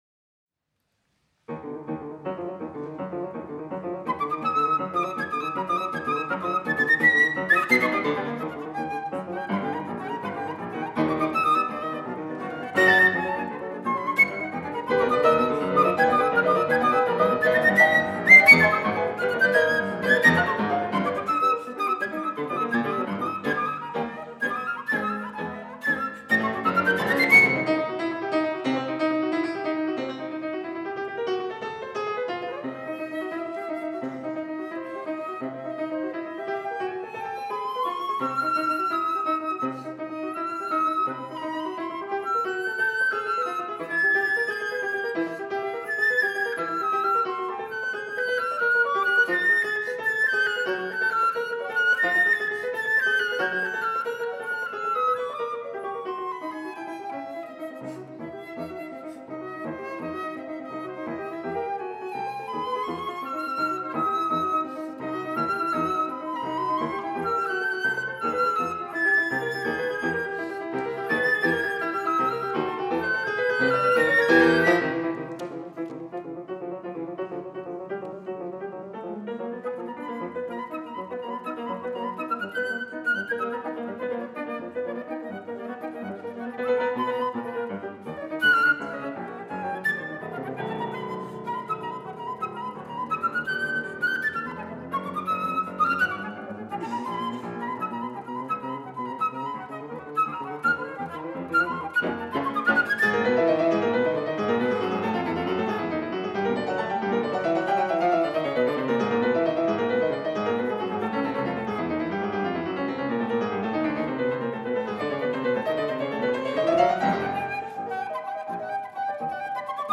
5. Internationaler Wettbewerb 2003